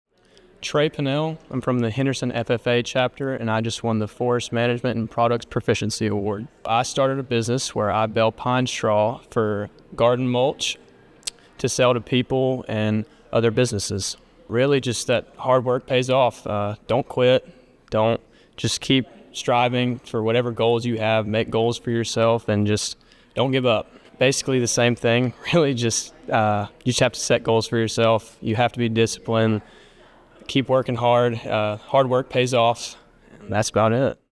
By Published On: October 25th, 20240.4 min readCategories: Convention Audio